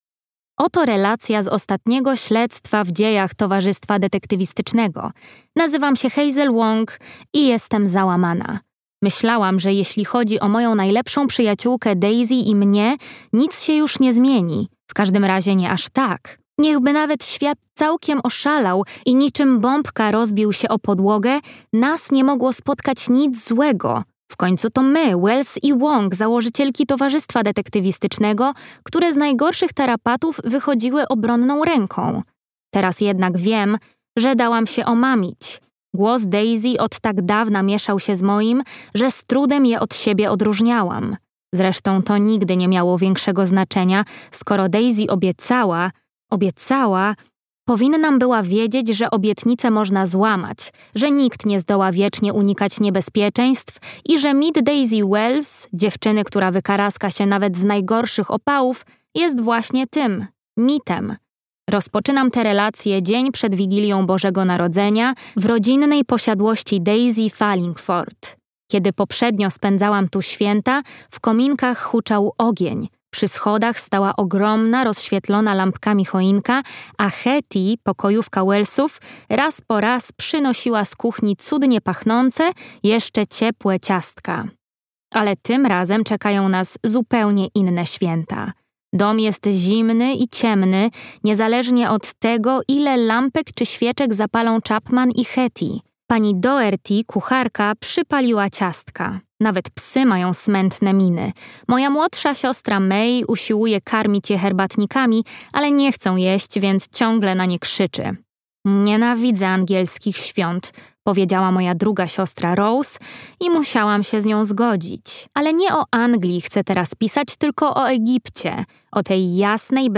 Śmierć stawia żagle. Zbrodnia niezbyt elegancka, tom 10 - Robin Stevens - audiobook